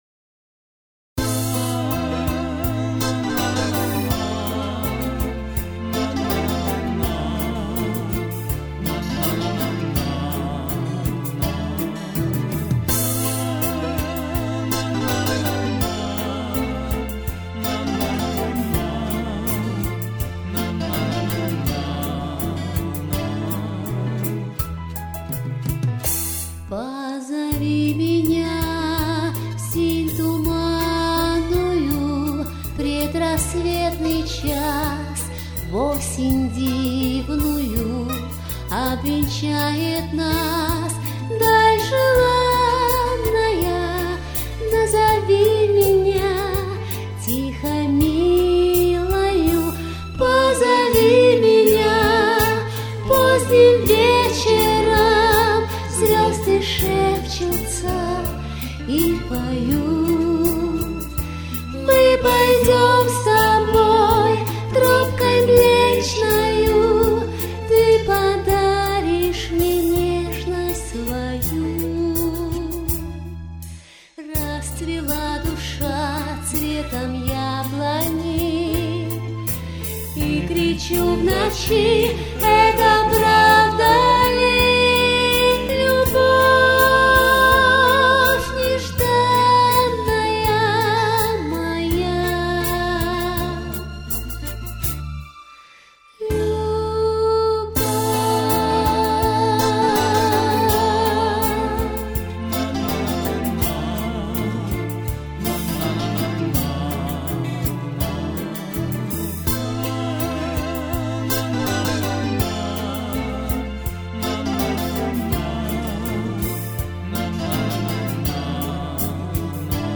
Авторские песни